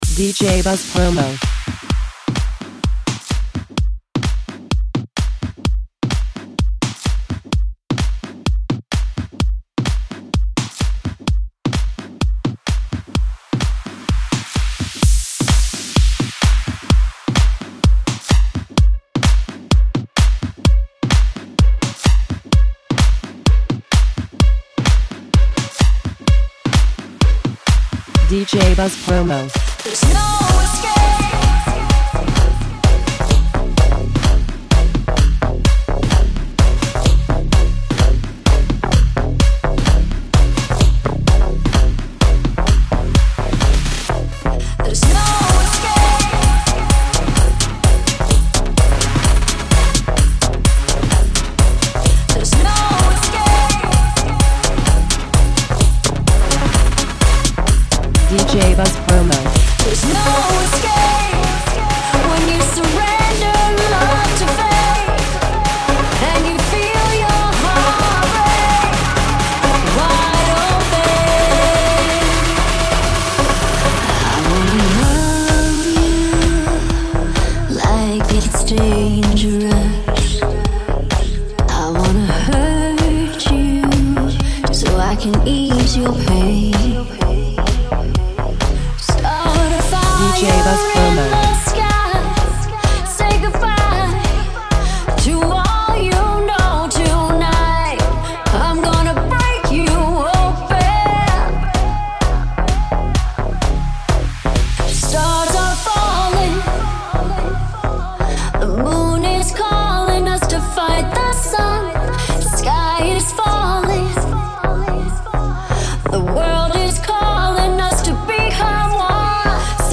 Huge Remix Pack!